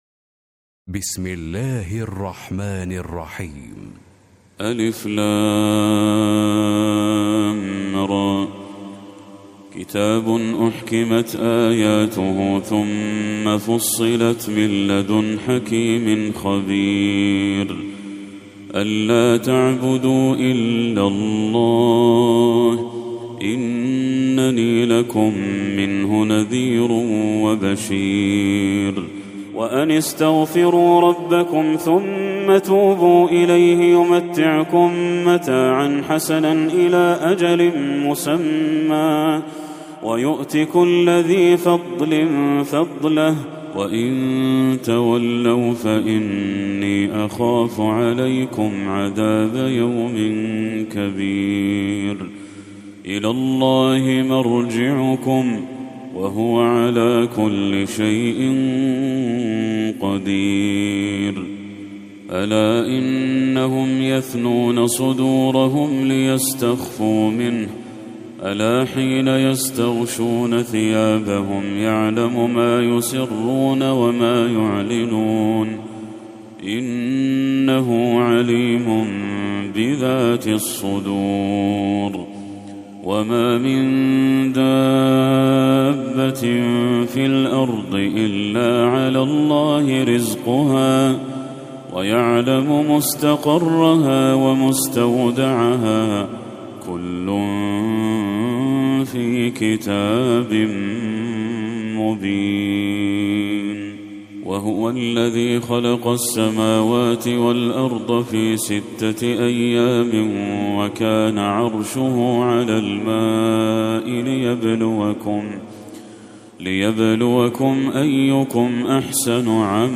سورة هود Surat Hud > المصحف المرتل